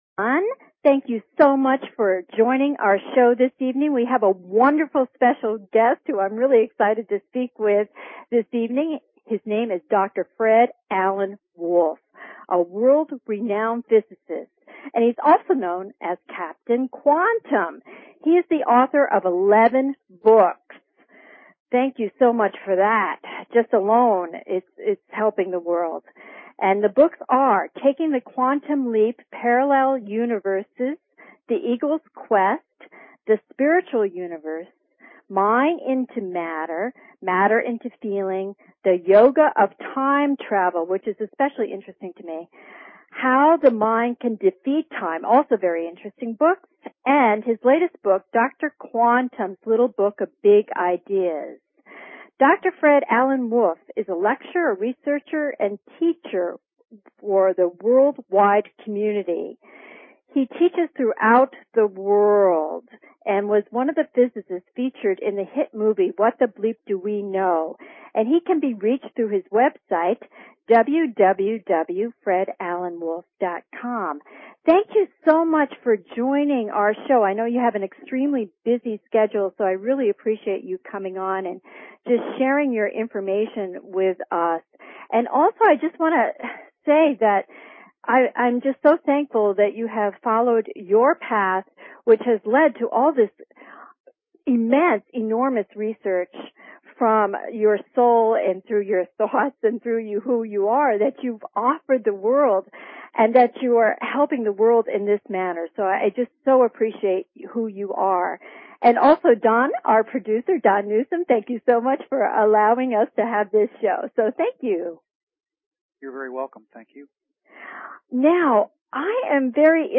Interview w/ Dr. Fred Alan Wolf